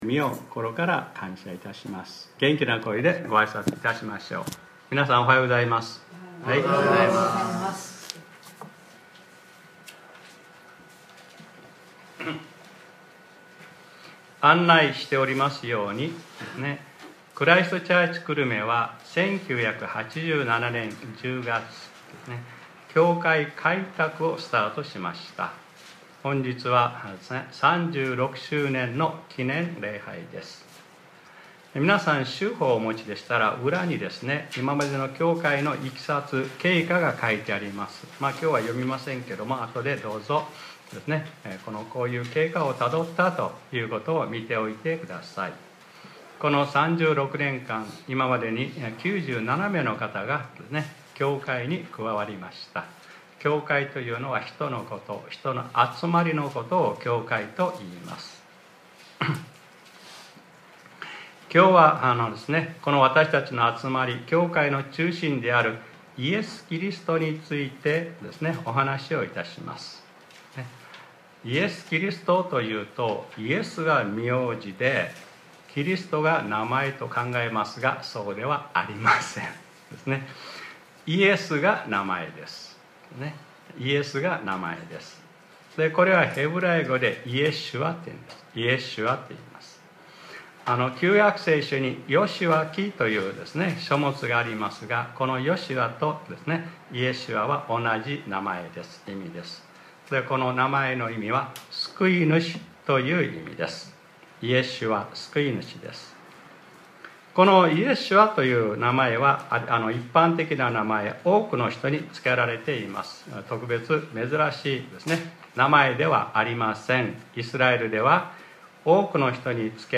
2023年10月01日（日）礼拝説教『 イエス・キリスト：３６周年記念礼拝 』